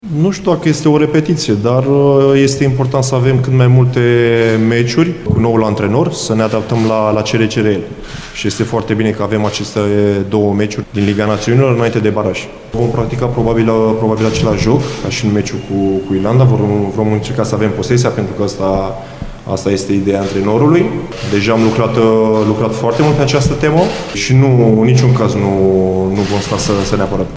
Portarul echipei naţionale de fotbal a României, Ciprian Tătăruşanu, a declarat că în partida cu Austria, de astăzi, tactica formaţiei pregătite de Mirel Rădoi va fi aceeaşi din partida cu Irlanda de Nord, să aibă posesia mingii cât mai mult timp, fără a se apăra în exces, chiar dacă evoluează în deplasare.